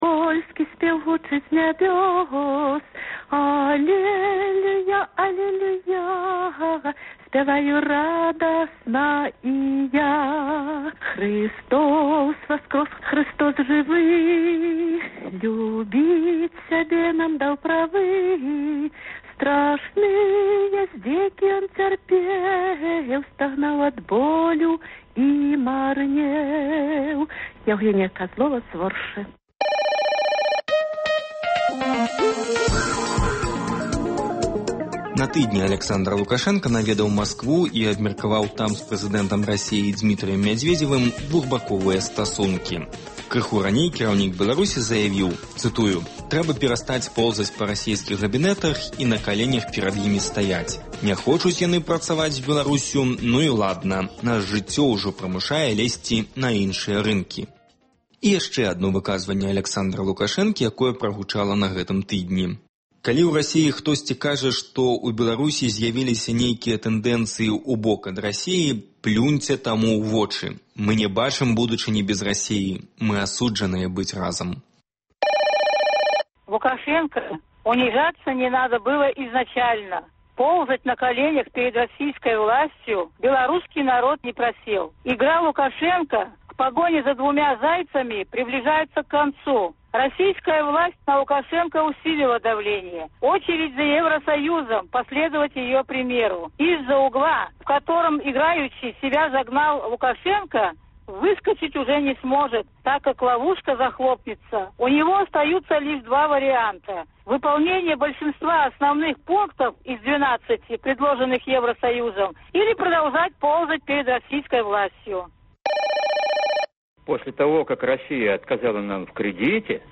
Агляд тэлефанаваньняў слухачоў за тыдзень